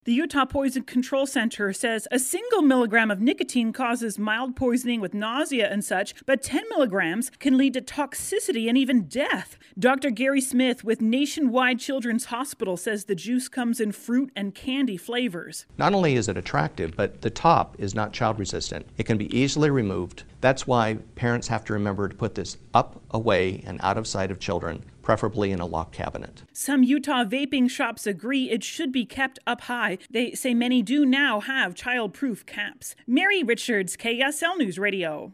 Utah's Noon News